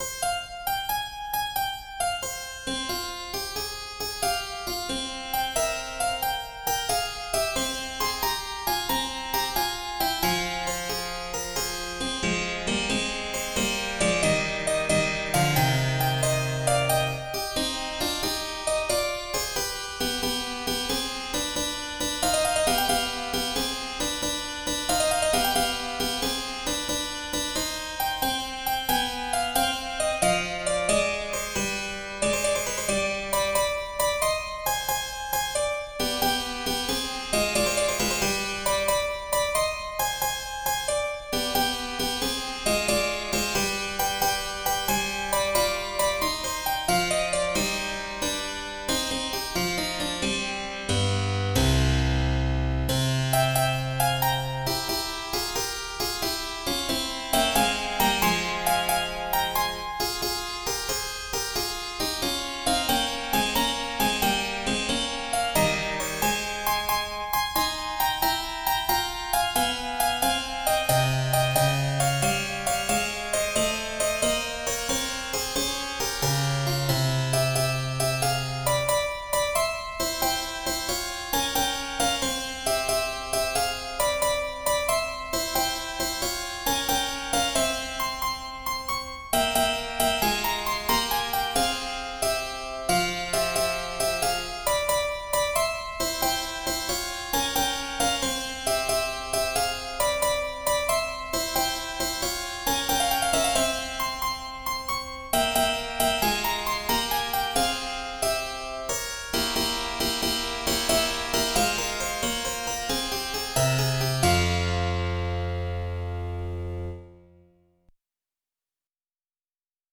in F minor: Andante